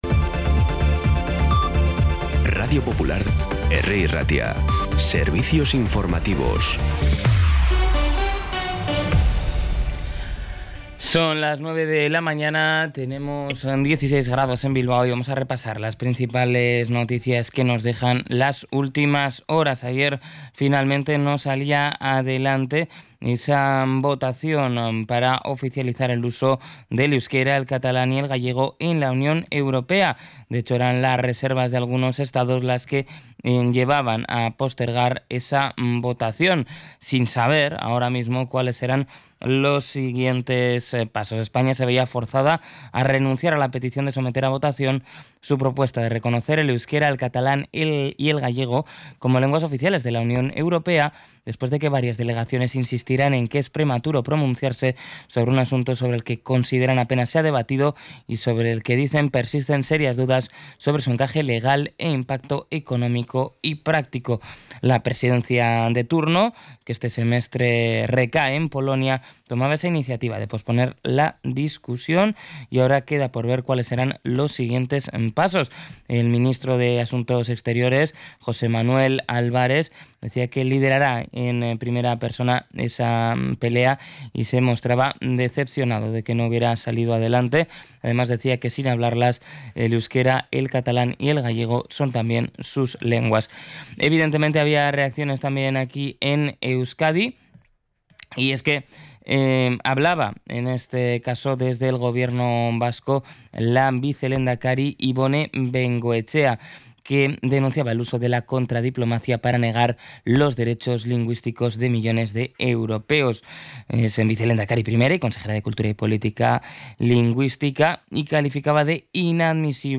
Los titulares actualizados con las voces del día.